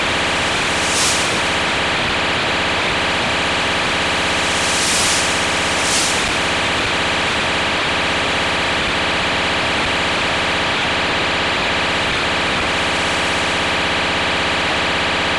UnknownTrunking_Sound.mp3